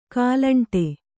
♪ kālaṇṭe